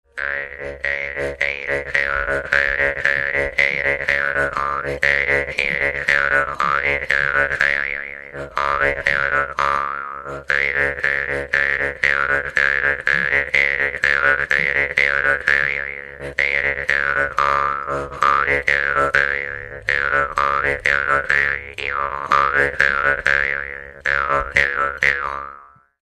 Dallampélda: Hangszeres felvétel
Moldva és Bukovina - Moldva - Klézse
doromb
Műfaj: Gergely-tánc
Stílus: 7. Régies kisambitusú dallamok
Kadencia: #4 (#4) 5 1